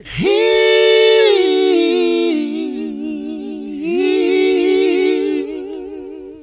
d'angelo vocal.wav